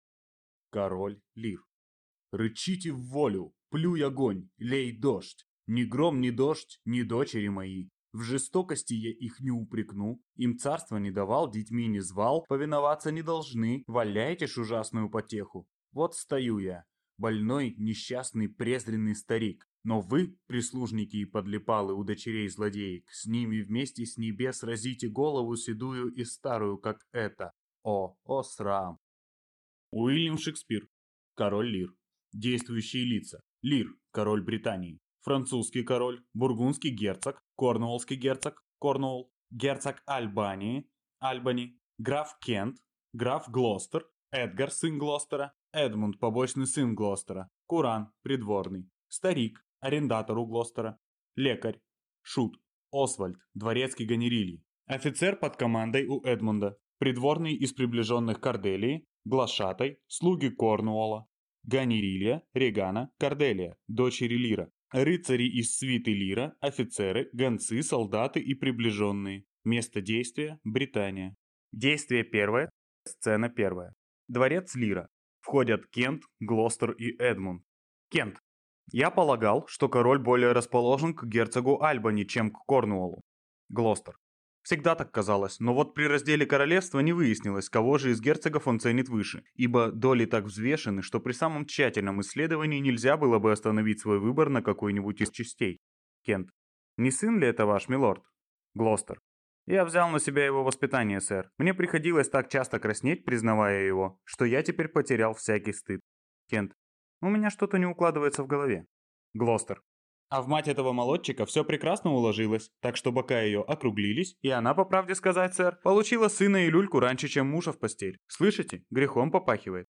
Аудиокнига Король Лир | Библиотека аудиокниг